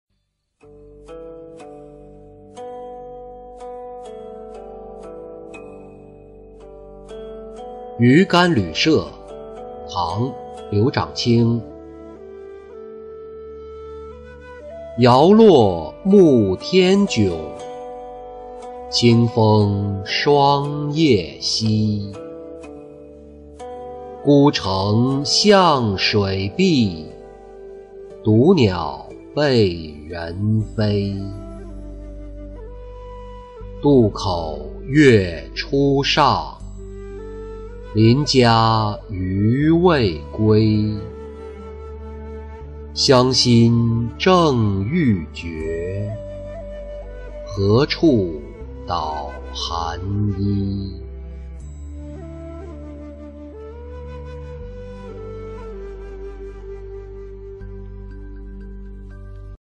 馀干旅舍-音频朗读